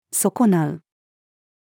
損なう-female.mp3